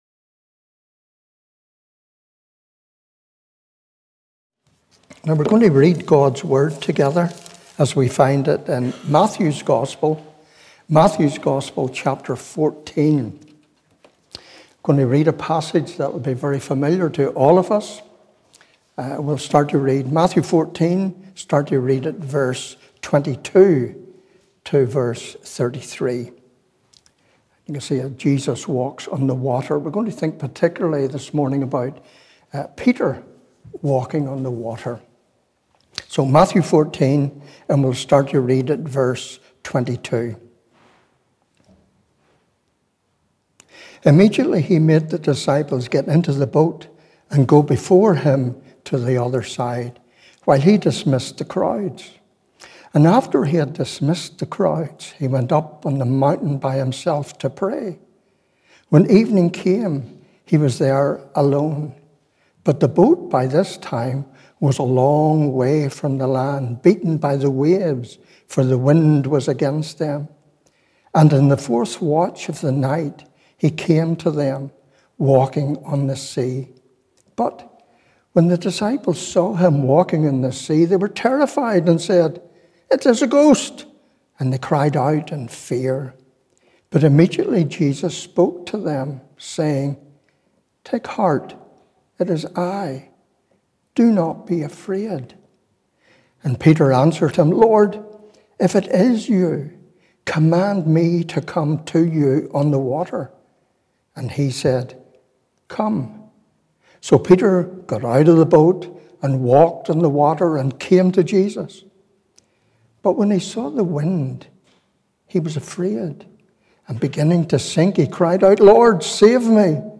Morning Service 1st May 2022 – Cowdenbeath Baptist Church